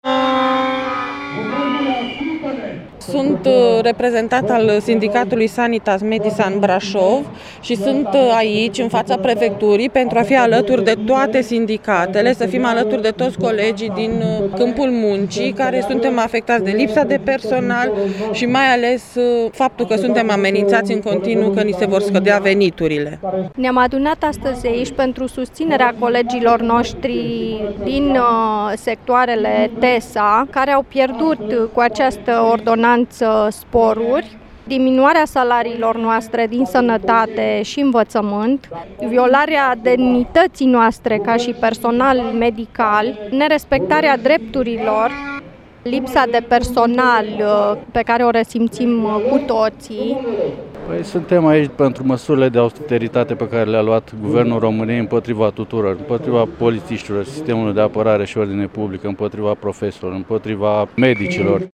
Este apelul sindicaliștilor brașoveni din cadrul Confederației Cartel Alfa, care au protestat în această dimineață în fața Prefecturii Brașov față de măsurile de austeritate anunțate de Guvernul Bolojan: